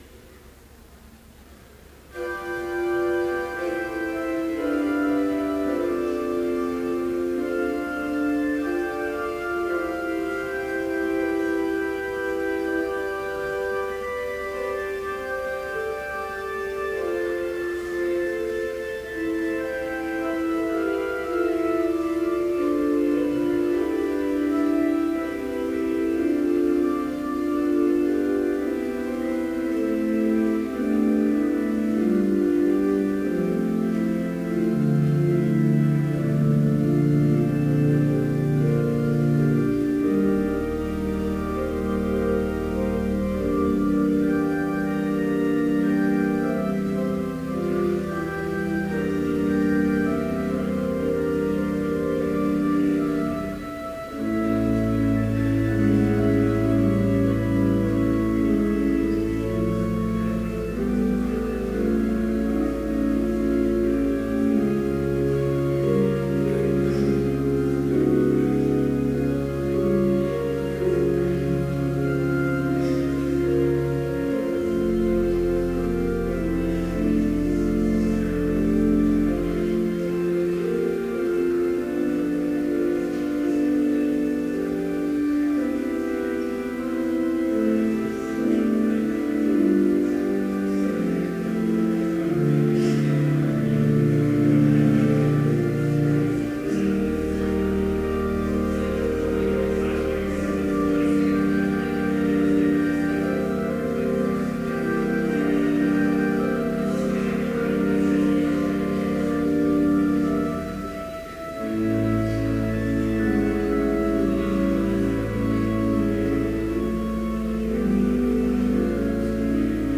Complete service audio for Chapel - April 14, 2015
Prelude Hymn 366, vv. 1, 2, 5 & 6, Ye Sons and Daughters of the King Reading: John 20:26-29 Homily Prayer #65, p. 155 (in unison) Hymn 366, vv. 7-10, No longer Thomas… Blessing Postlude